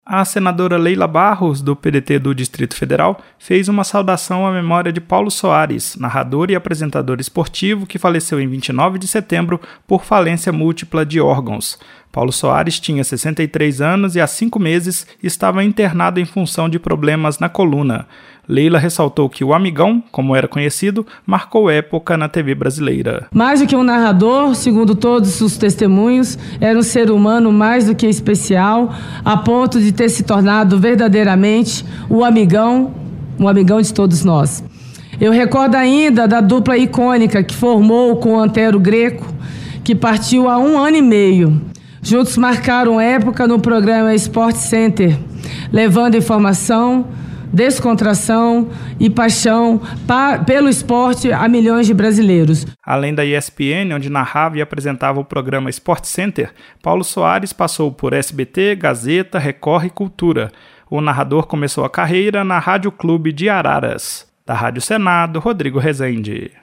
A senadora Leila Barros (PDT-DF) prestou homenagem em nome da Comissão de Esporte à memória de Paulo Soares, o “amigão”, narrador e apresentador esportivo. Leila destacou a histórica dupla de Paulo com Antero Greco na apresentação do programa Sportcenter, da ESPN.